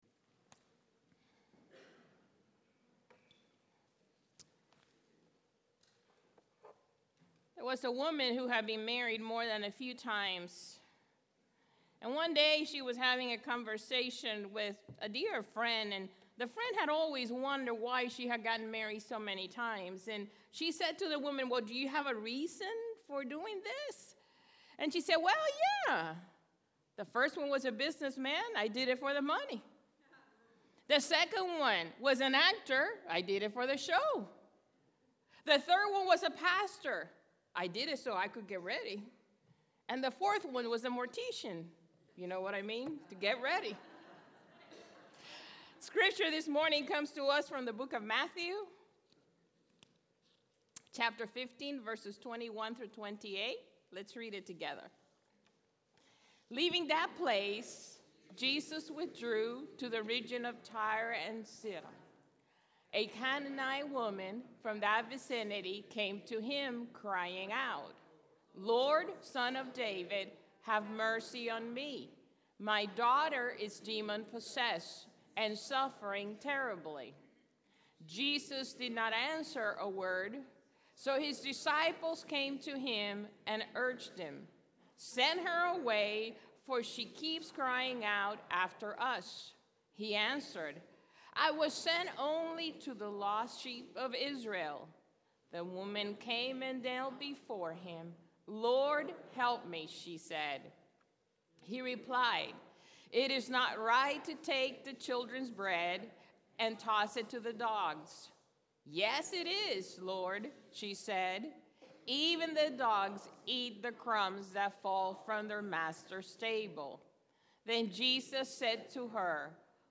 East Naples United Methodist Church Sermons